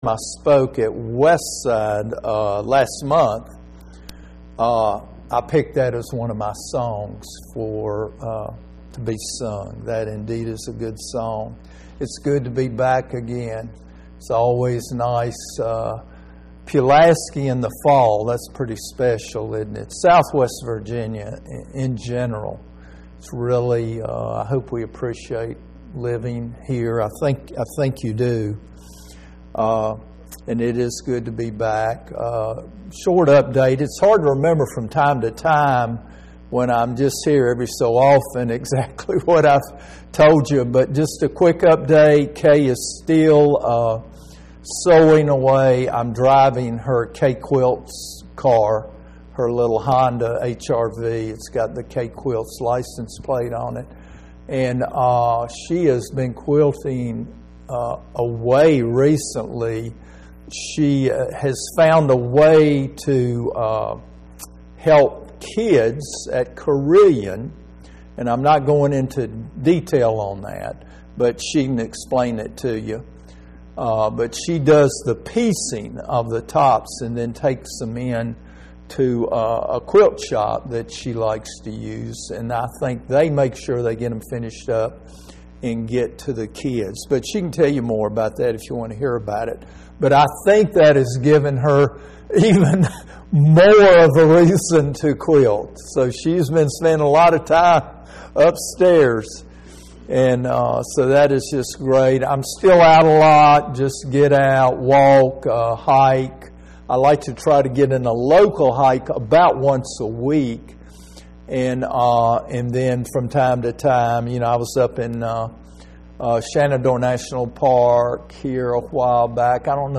Ruth – Bible Study